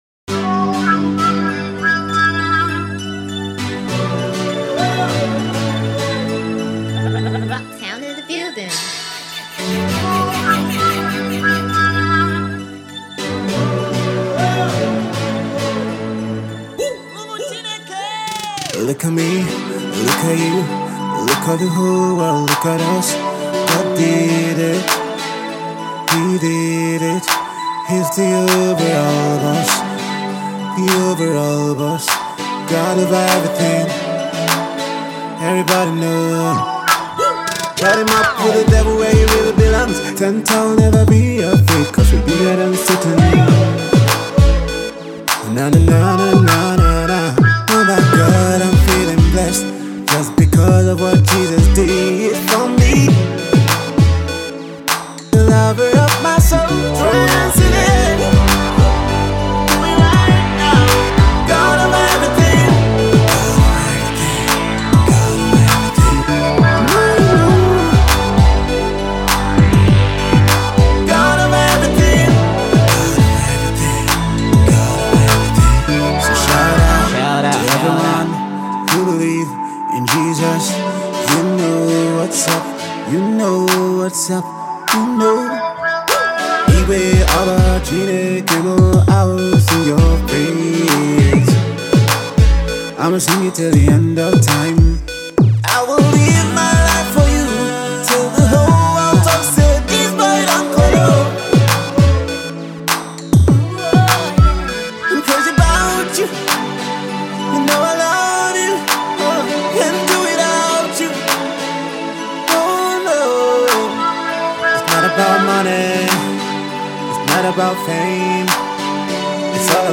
It’s hip, it’s fresh and urban.